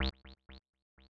ENE Acid C1.wav